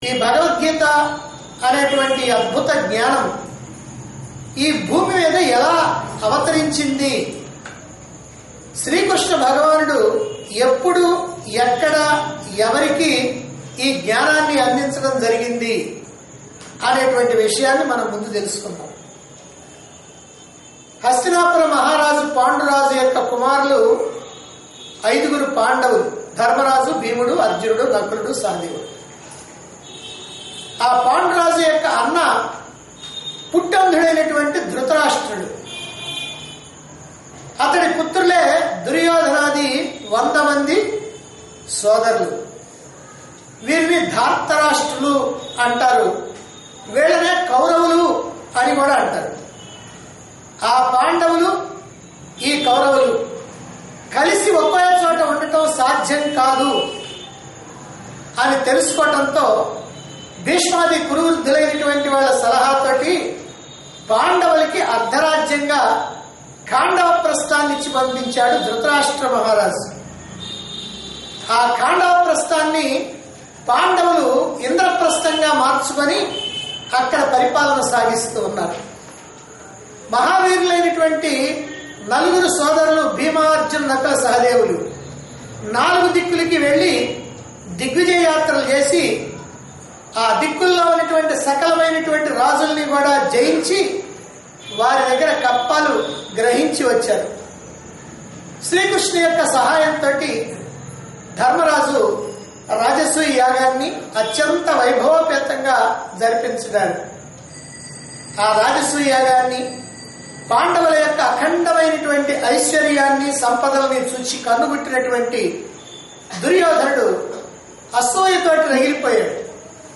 Discourse Conducted At Chilakaluripet, Guntur Dt. Andhra Pradesh.